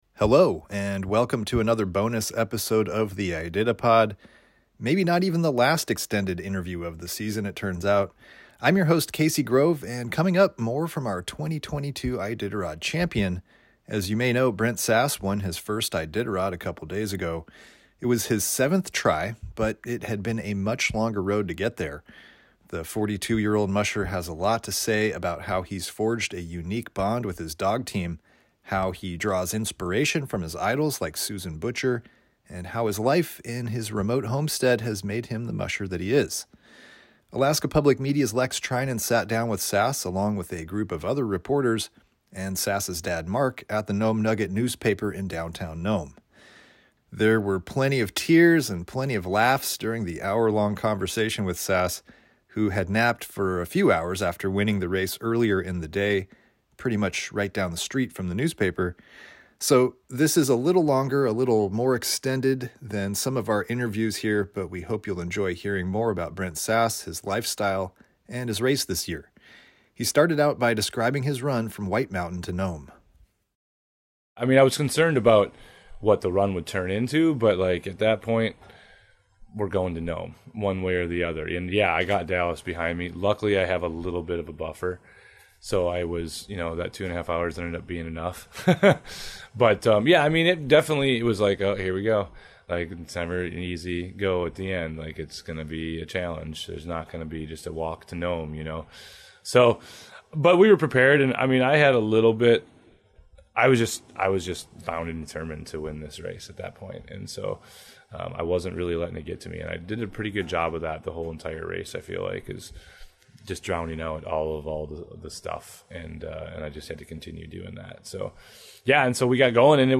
Brent Sass interview in Nome